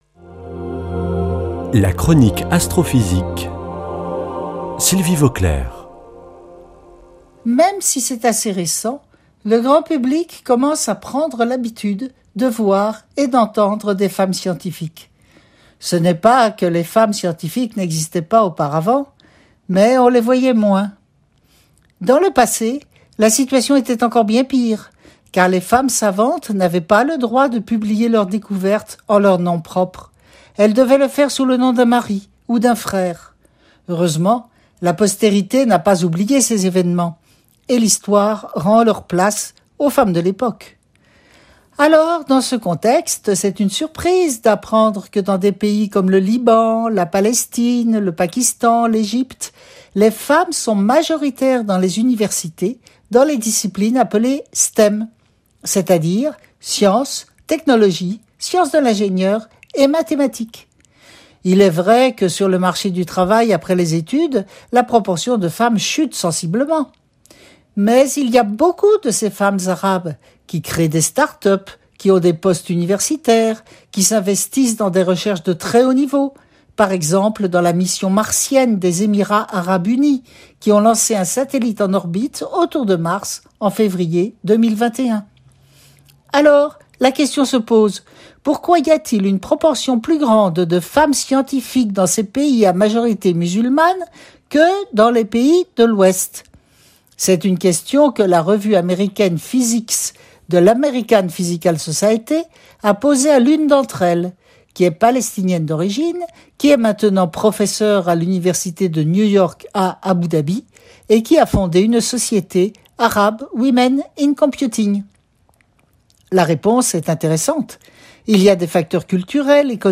lundi 21 juin 2021 Chronique Astrophysique Durée 3 min
[ Rediffusion ] Hommage aux nombreuses femmes arabes scientifiques de haut niveau